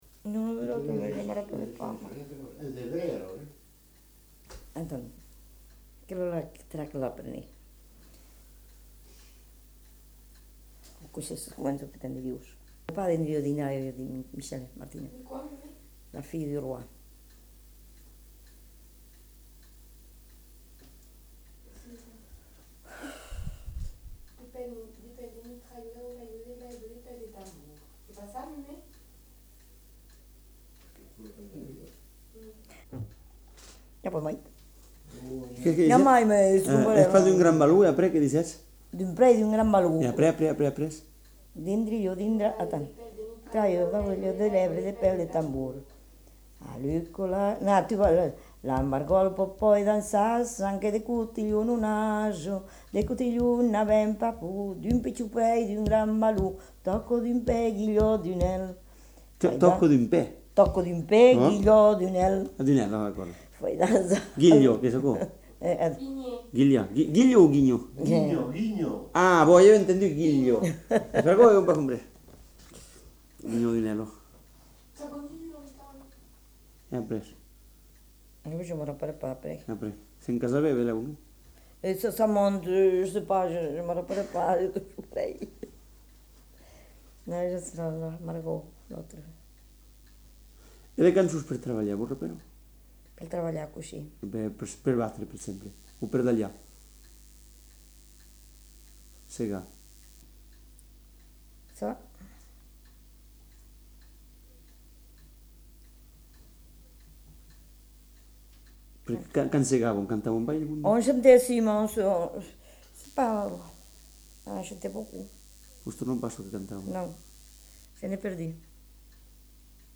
Aire culturelle : Lauragais
Lieu : Lanta
Genre : chant
Effectif : 1
Type de voix : voix de femme
Production du son : chanté